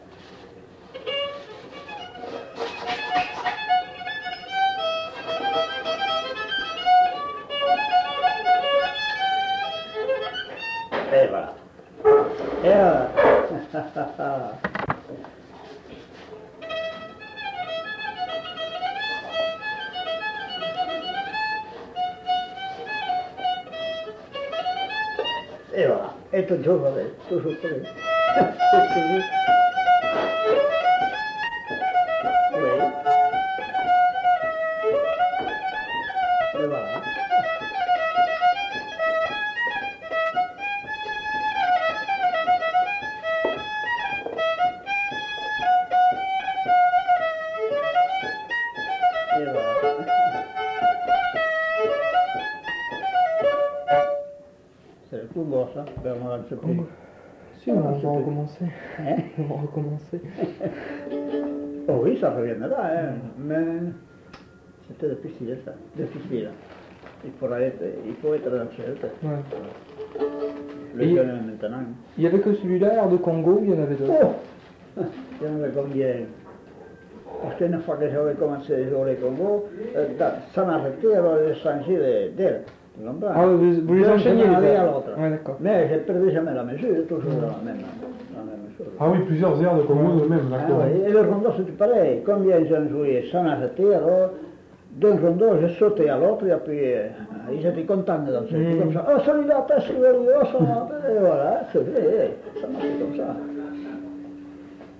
Répertoire de danses des Lugues joué au violon
enquêtes sonores